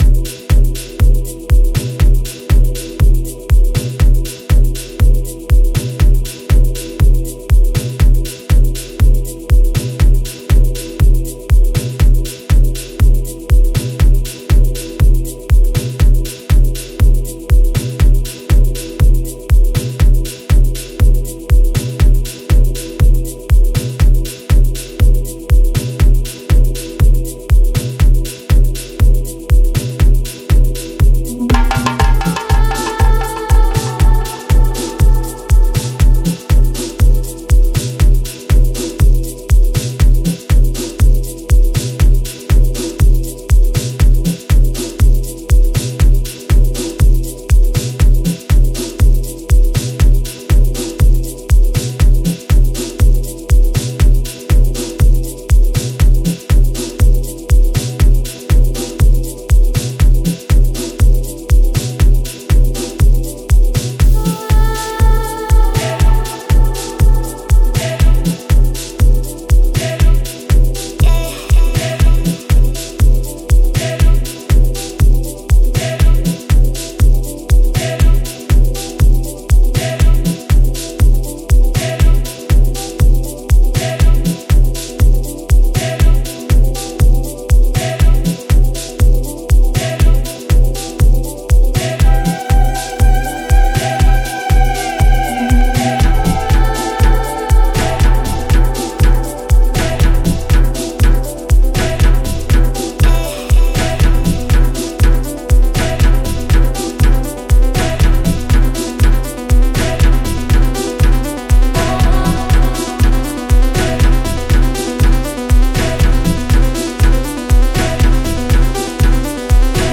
Afro House track